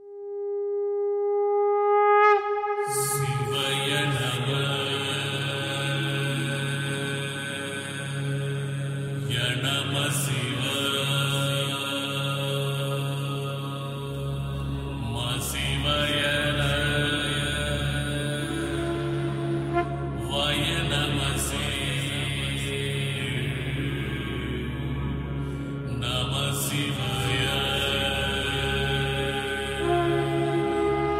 Listen to a singing of all the five mantra together.